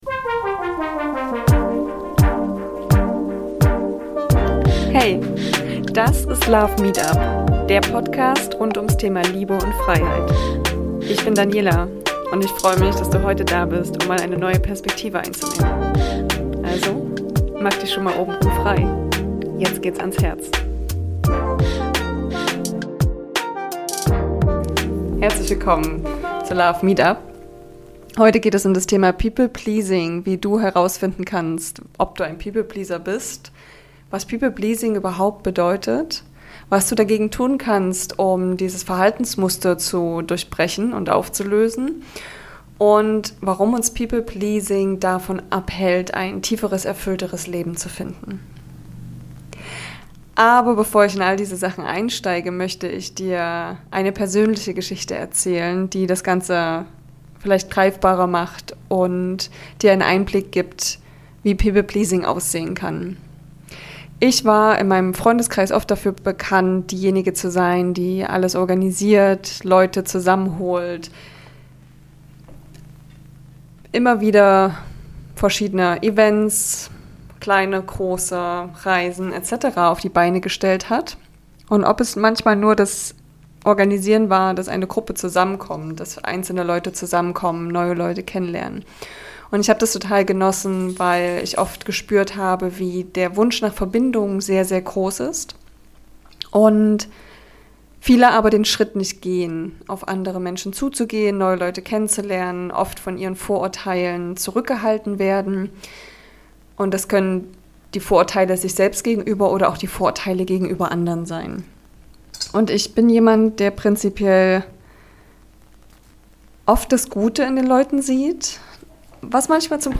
1 Duftkerzen, die weit mehr als nur herrlich riechen | Interview